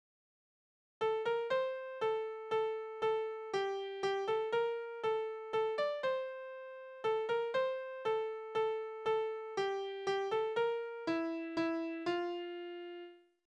Kindertänze: Die Tiroler
Tonart: F-Dur
Taktart: 3/4
Tonumfang: kleine Septime
Besetzung: vokal